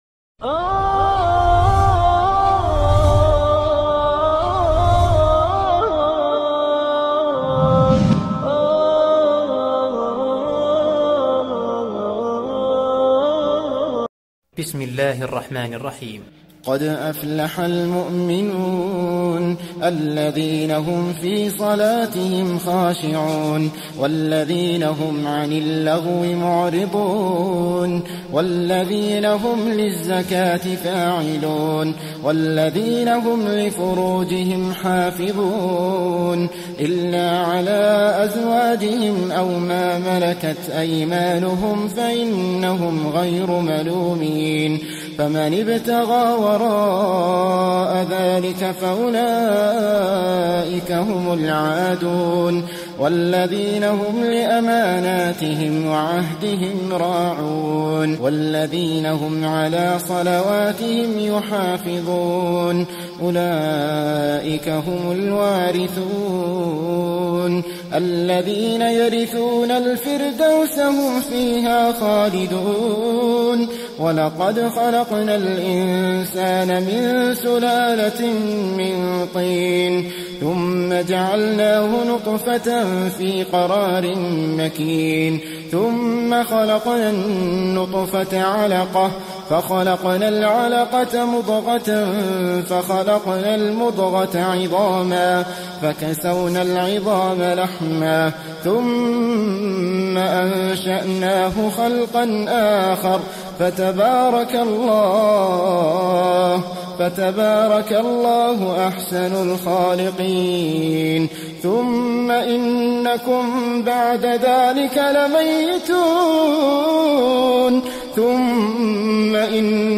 Quran Recitation / Tilawat of Surah Al Muminum